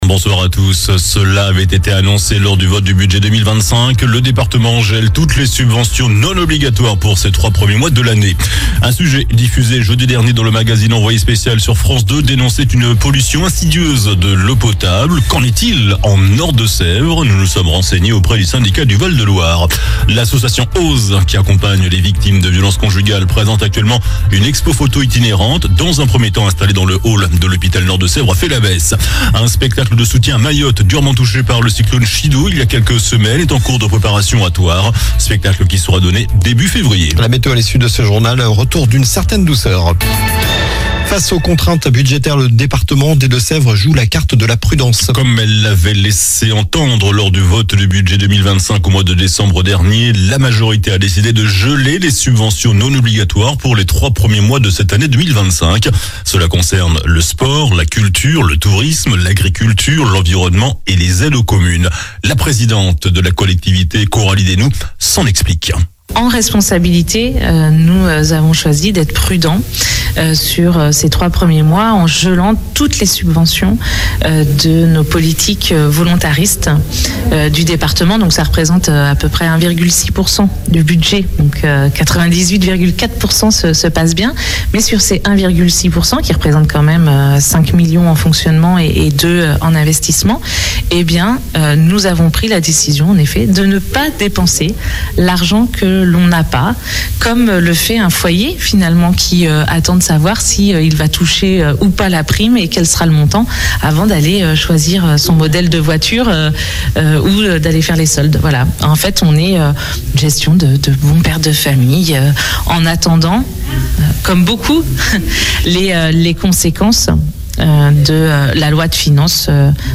Journal du lundi 20 janvier (soir)